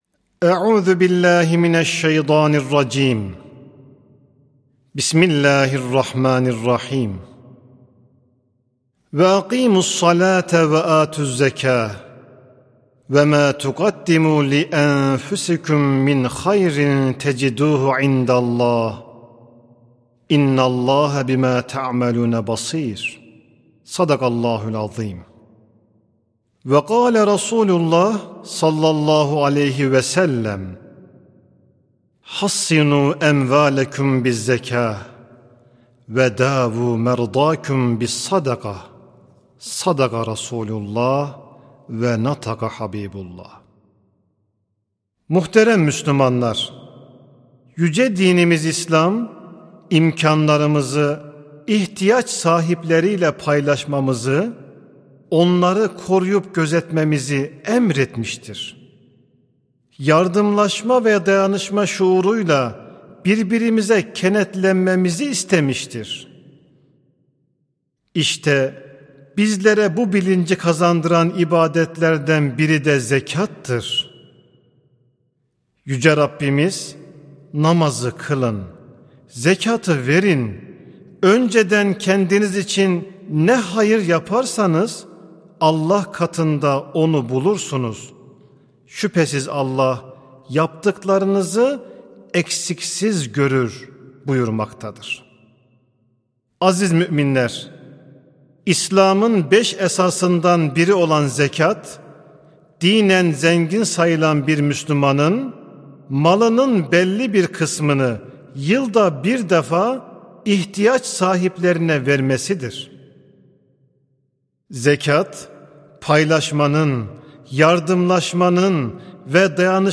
Hutbeler
Sesli Hutbe (İyilik Köprüsü, Zekat ve Fıtır Sadakası).mp3